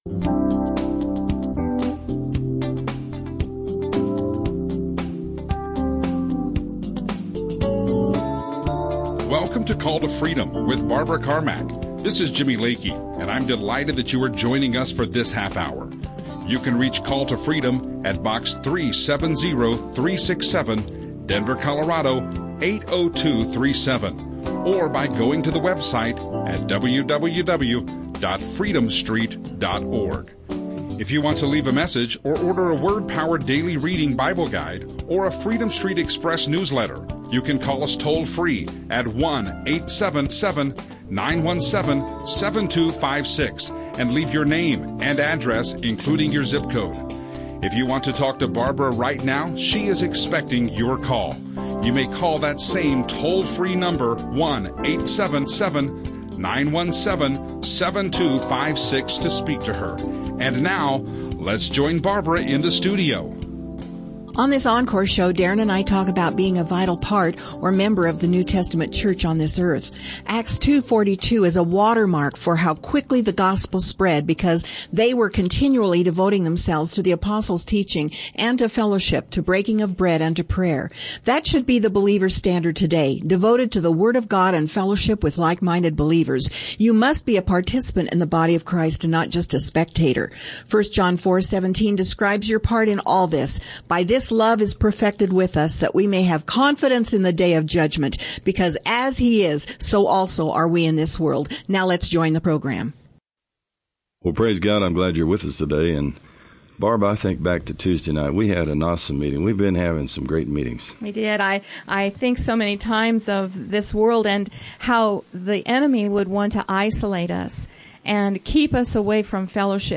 Call to Freedom Christian talk radio